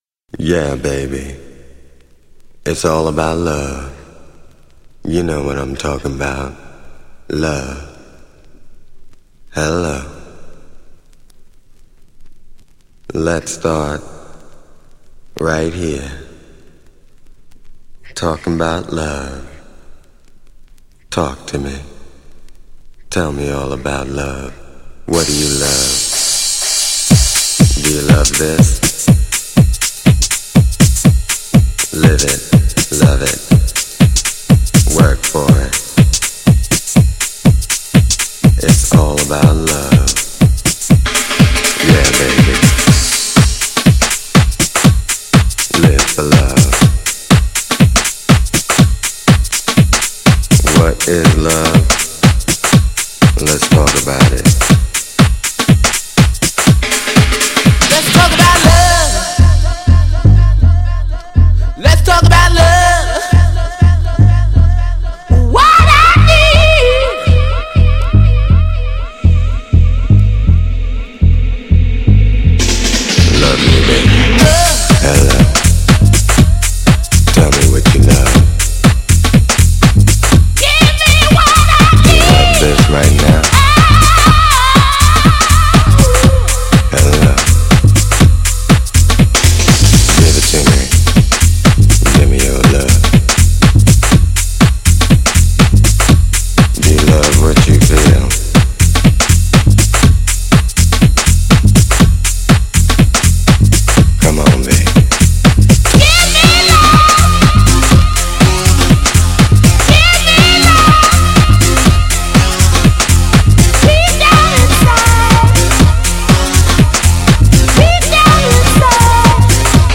B面はヘビーなDUB HOUSE!!
GENRE House
BPM 81〜85BPM